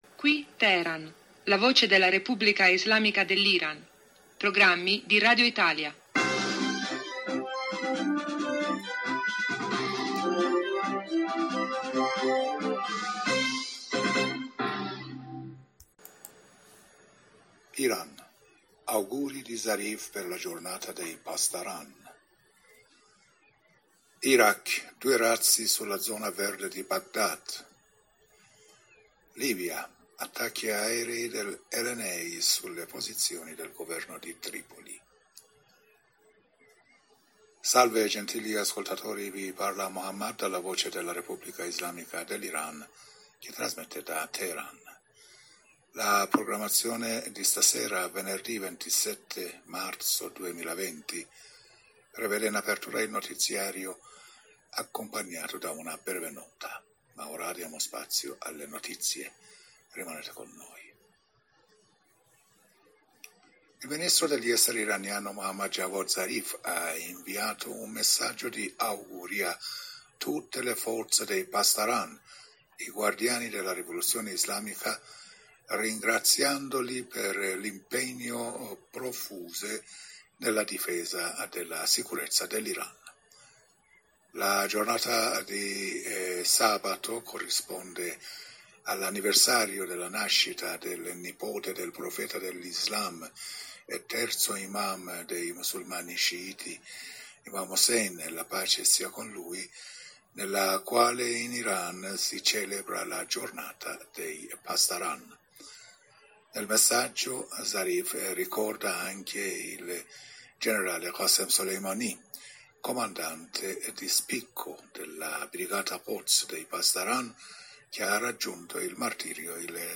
Venerdi 27 marzo 2020 giornale radio serale